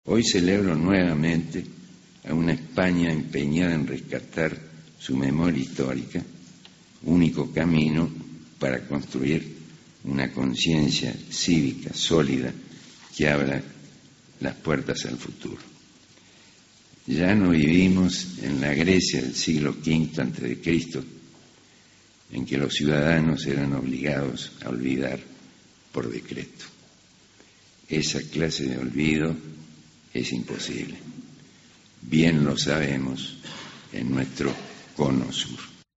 Parte del discurso del poeta Juan Gelman en el Premio Cervantes 2007